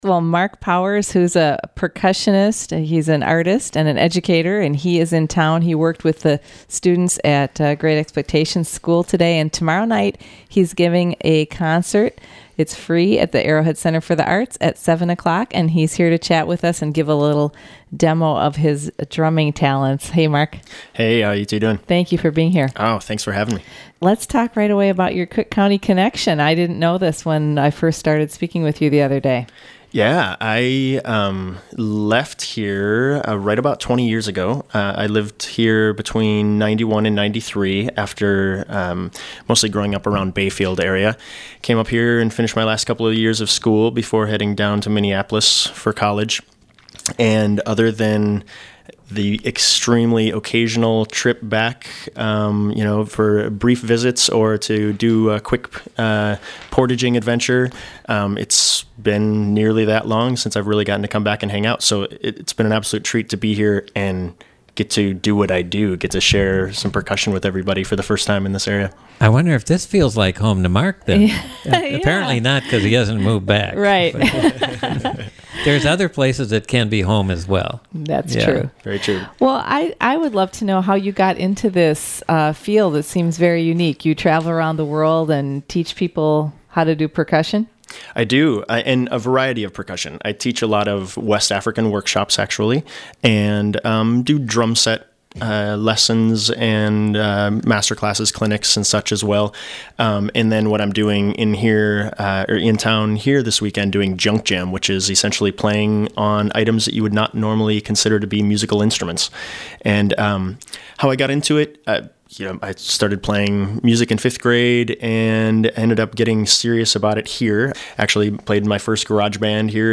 makes music on everyday objects